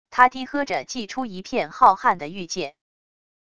他低喝着祭出一片浩瀚的域界wav音频生成系统WAV Audio Player